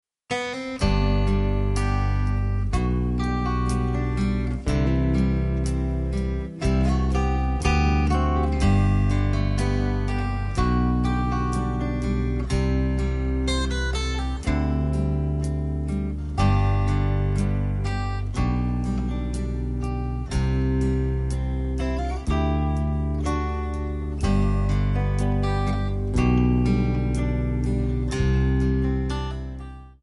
Bb
MPEG 1 Layer 3 (Stereo)
Backing track Karaoke
Country, 1990s